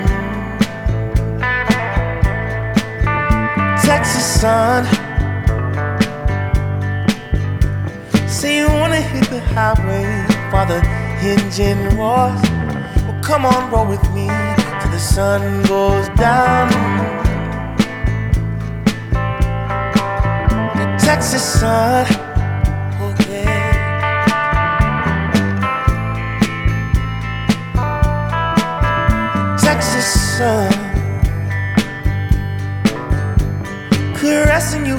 Жанр: Иностранный рок / Рок / Инди